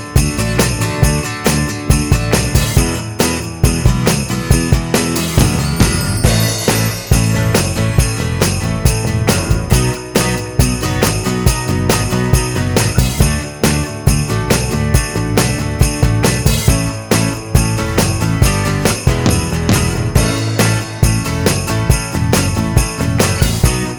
No Backing Vocals Rock 'n' Roll 3:22 Buy £1.50